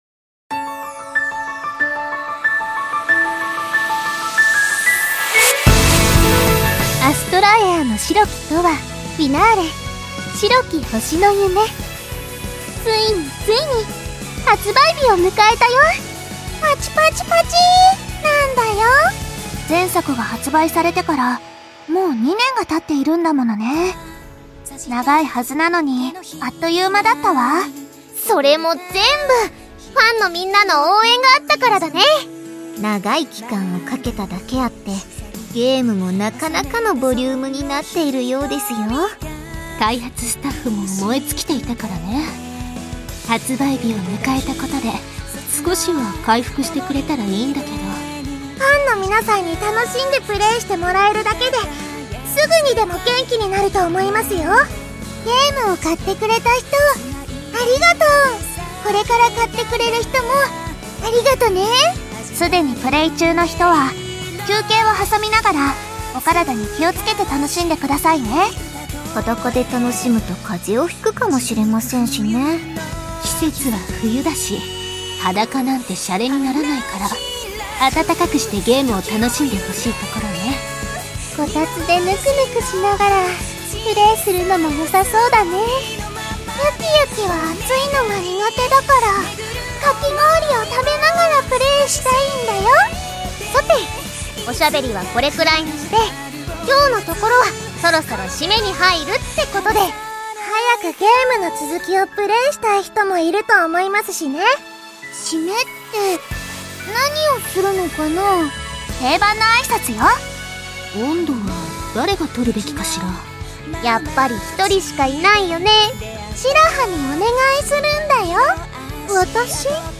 『アストラエアの白き永遠 Finale』 発売記念ボイスを公開